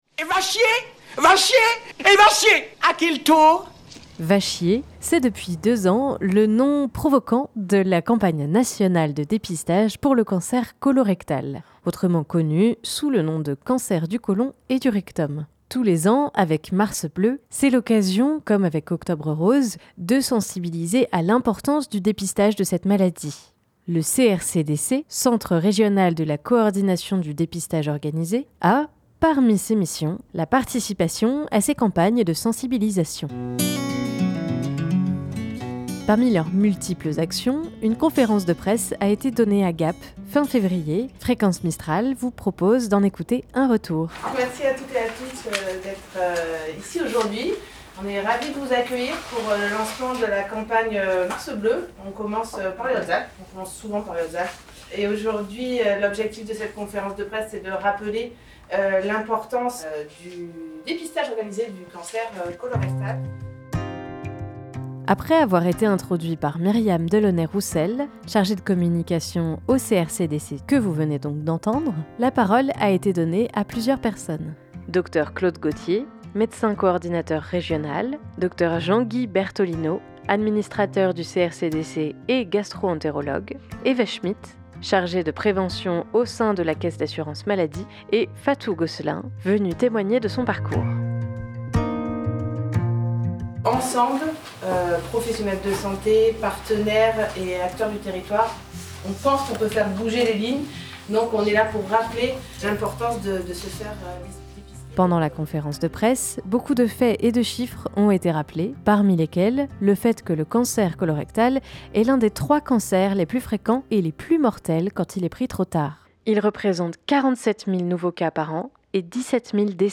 Pour mieux comprendre les tenants et aboutissants de ce dépistage du cancer colo-réctal, Fréquence Mistral vous propose un retour sur la conférence de presse donnée à Gap fin février.